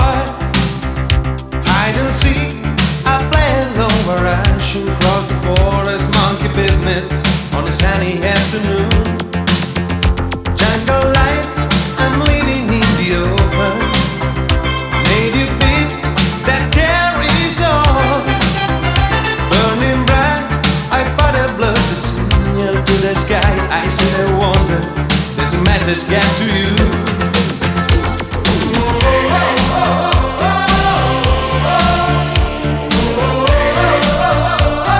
Dance Electronic Rock
Жанр: Рок / Танцевальные / Электроника